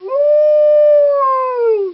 wolf.wav